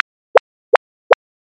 効果音
紙を破る音、ゴクゴク飲む音、トイレの音など・・・、ありそうでなさそな音40点を集めたゲーム用効果音素材集！
足音1 02足音2 03足音3 04拾う 05やめる